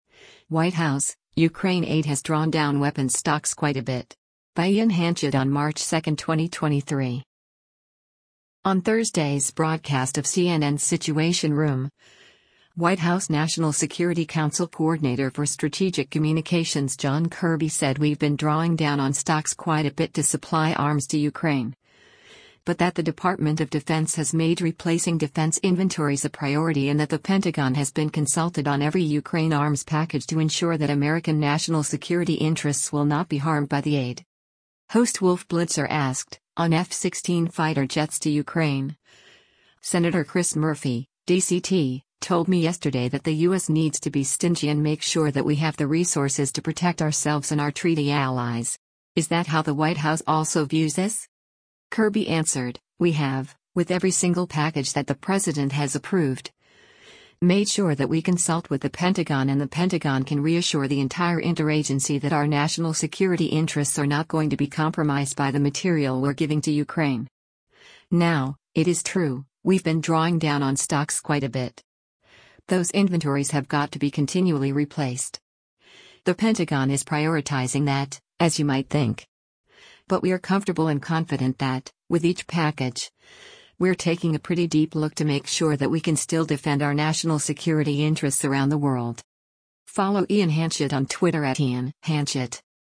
On Thursday’s broadcast of CNN’s “Situation Room,” White House National Security Council Coordinator for Strategic Communications John Kirby said “we’ve been drawing down on stocks quite a bit” to supply arms to Ukraine, but that the Department of Defense has made replacing defense inventories a priority and that the Pentagon has been consulted on every Ukraine arms package to ensure that American national security interests will not be harmed by the aid.